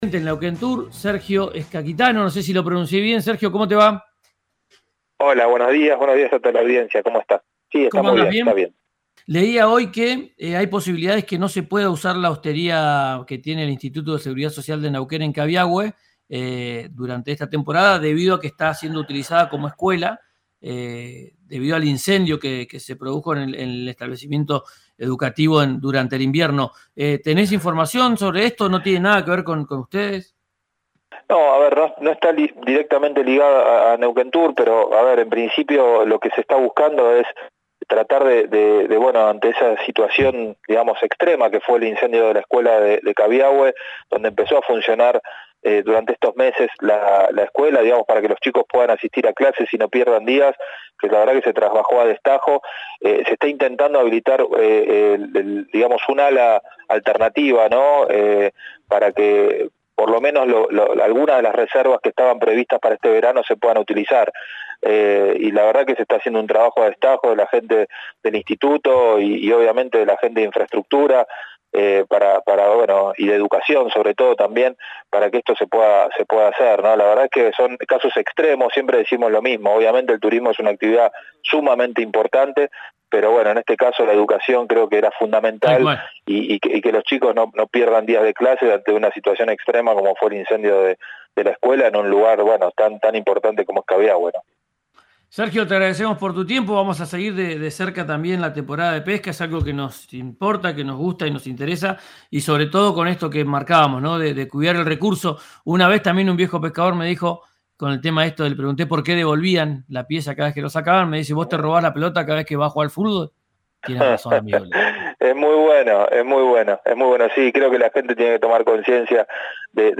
El presidente del ente estatal de promoción turística NeuquenTur, Sergio Sciacchitano, habló con RÍO NEGRO RADIO y explicó la situación actual.
Escuchá Sergio Sciaccitano, del NeuquenTur, en “Ya es tiempo”, por RÍO NEGRO RADIO